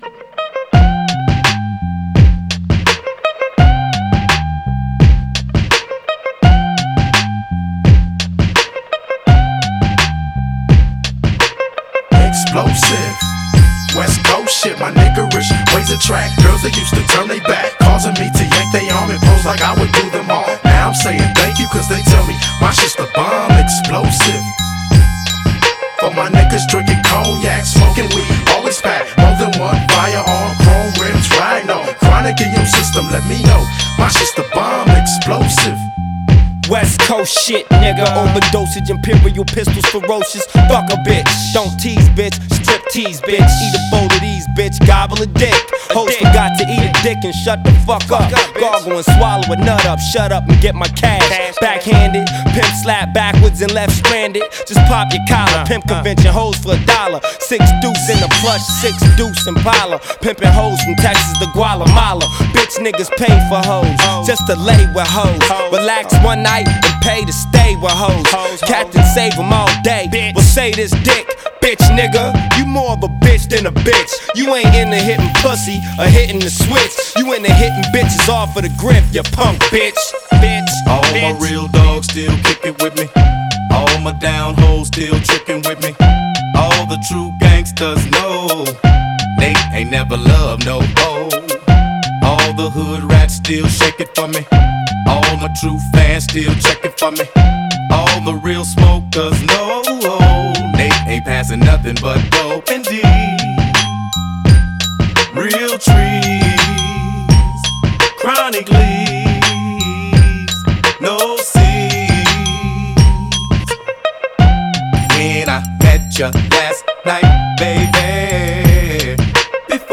هیپ هاپ hip hop رپ rap